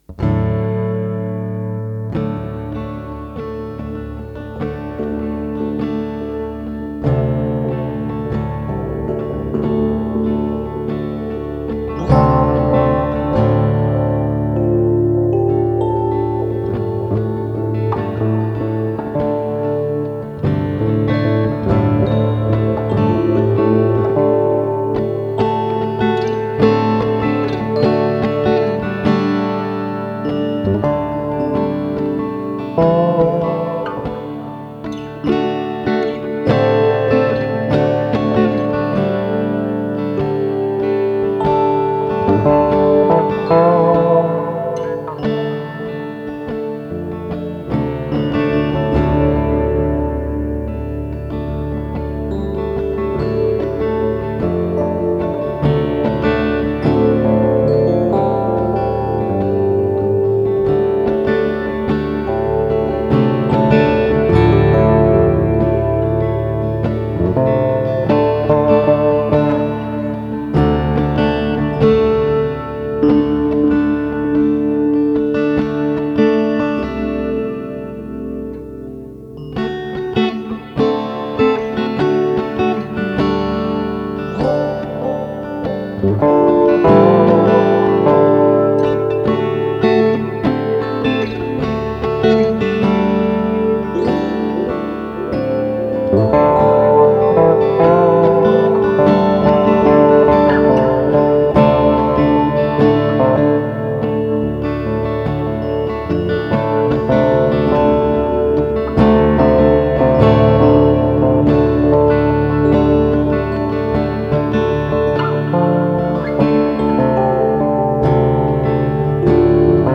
Chiling Gitarre mit E-Piano und Delay / Datum: 05.02.2025